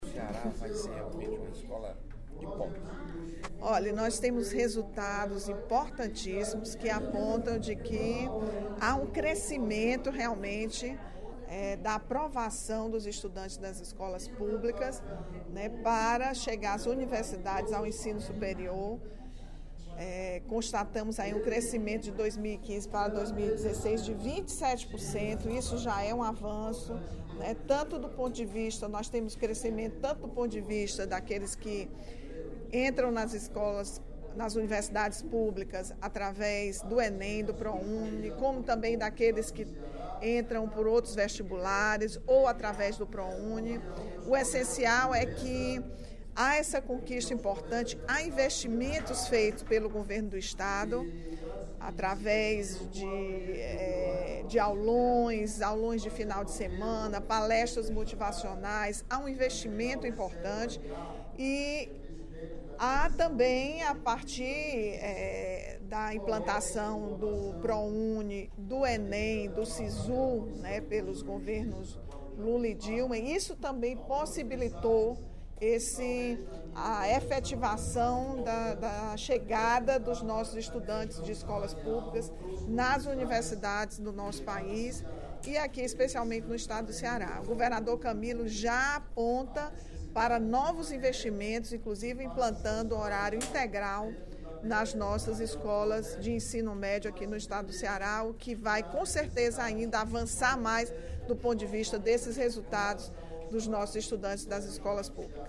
A deputada Rachel Marques (PT) comemorou, no primeiro expediente da sessão plenária da Assembleia Legislativa desta sexta-feira (03/03), o crescimento no índice de aprovação no Exame Nacional do Ensino Médio (Enem) dos estudantes das escolas estaduais em faculdades públicas e privadas de todo o País.